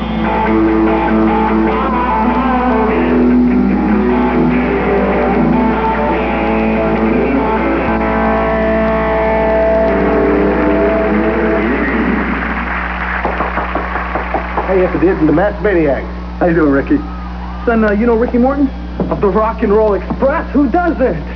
mean riff!
Rock & Roll riff
Canned applause follows.